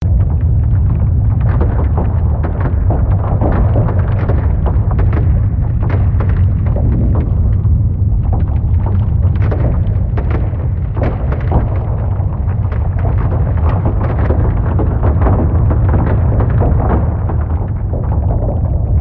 fx_mp2_mono_G_Volcano_02.wav